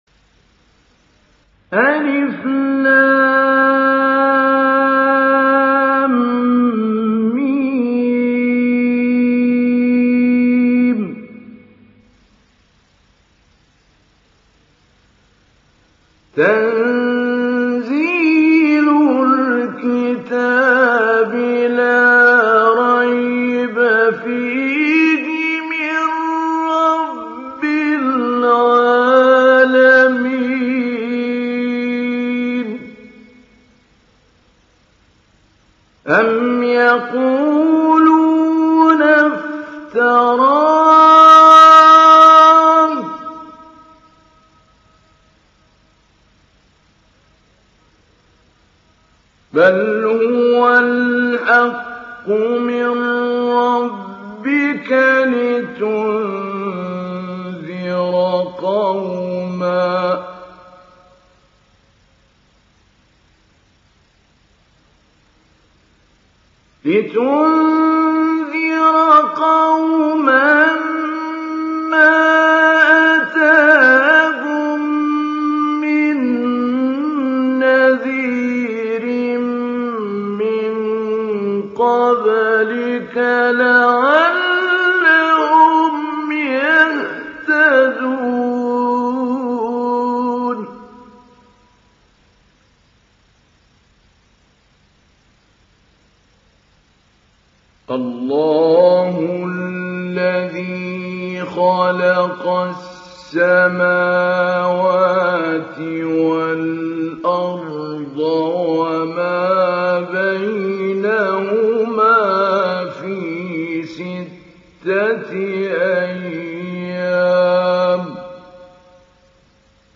Secde Suresi İndir mp3 Mahmoud Ali Albanna Mujawwad Riwayat Hafs an Asim, Kurani indirin ve mp3 tam doğrudan bağlantılar dinle
İndir Secde Suresi Mahmoud Ali Albanna Mujawwad